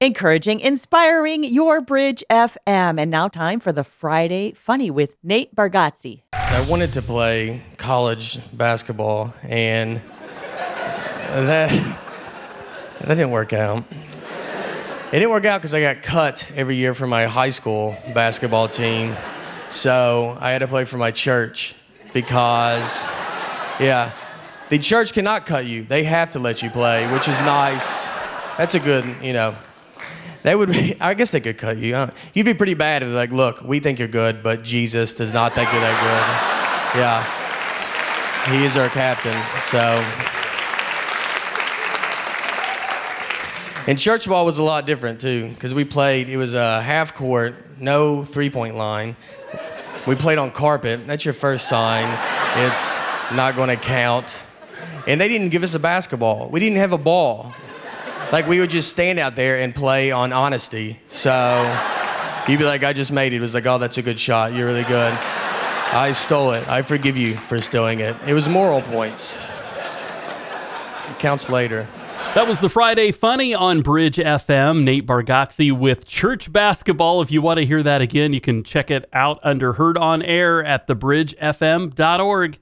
Friday Funny: Nate Bargatze “Church Ball”